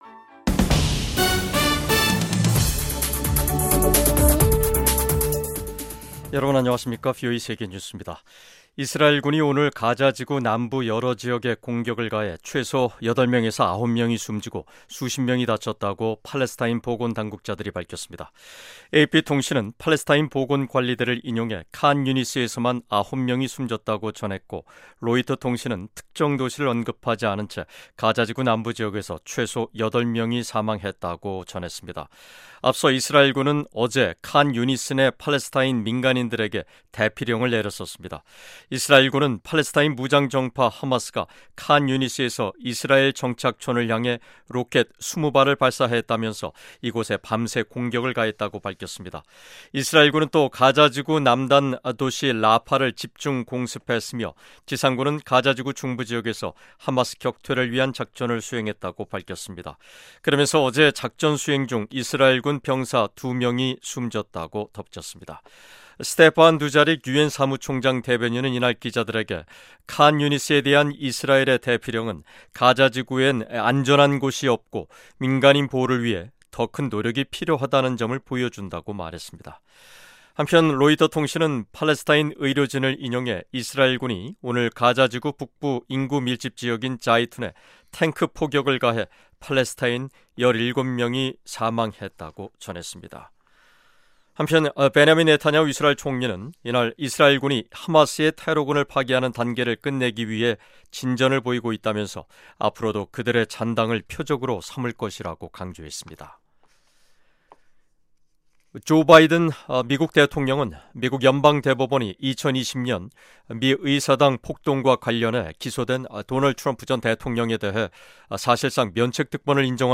세계 뉴스와 함께 미국의 모든 것을 소개하는 '생방송 여기는 워싱턴입니다', 2024년 7월 2일 저녁 방송입니다. '지구촌 오늘'에서는 이스라엘군이 팔레스타인 가자지구 칸유니스 지역에 대피 명령 후 공격한 소식 전해드리고 '아메리카 나우'에서는 미국 연방대법원이 대통령의 공적 행위에 대한 면책 권한은 전임 대통령도 적용된다며, 도널드 트럼프 전 대통령의 대선 결과 뒤집기 시도 혐의에 대한 면책특권 적용 여부를 하급심 법원으로 넘긴 소식 전해드립니다.